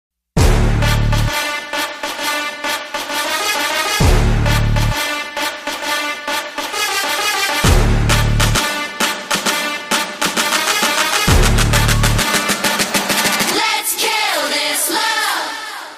• Качество: 128, Stereo
громкие
труба
K-Pop
Интересный проигрыш из песни популярной кей-поп группы